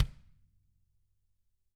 Index of /90_sSampleCDs/ILIO - Double Platinum Drums 1/CD4/Partition A/TAMA KICK D